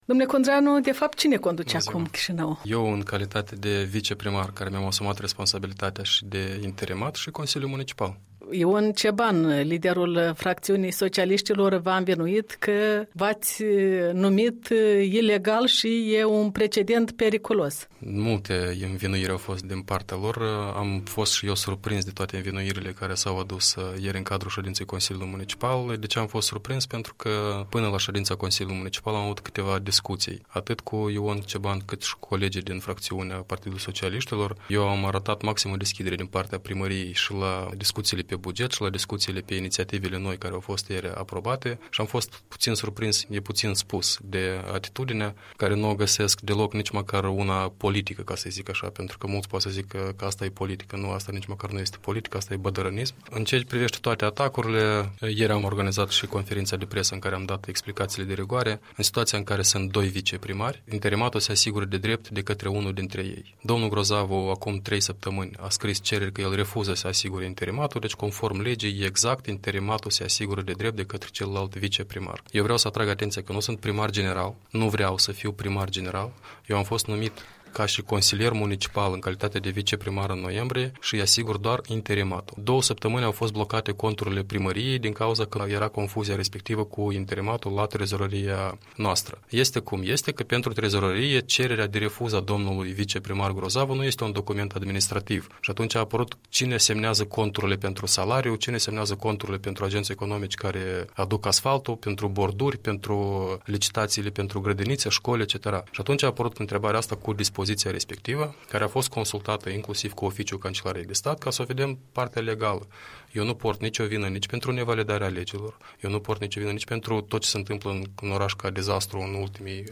Un interviu cu primarul general interimar al Capitalei.